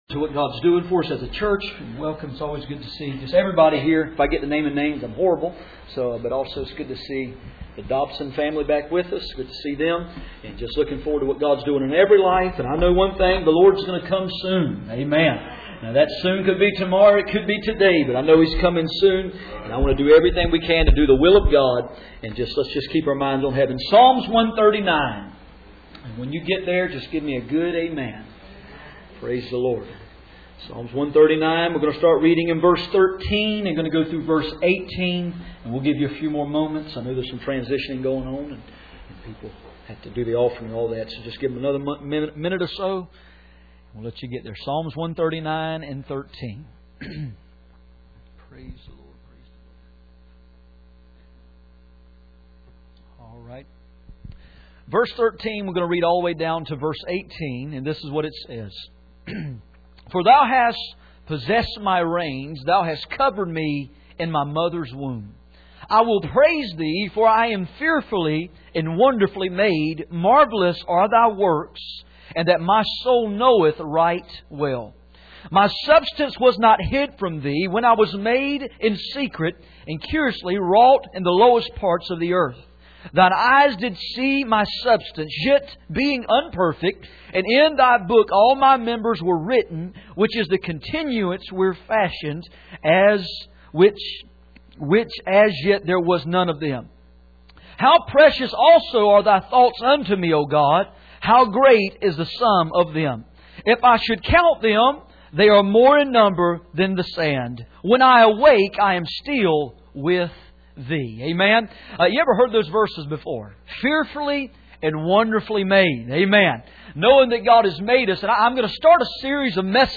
None Passage: Psalm 139:13-18 Service Type: Sunday Morning %todo_render% « Our need for fasting No fruit